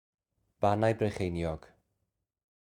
Bannau Brycheiniog (Ban-aye Bruch-ay-nee-og)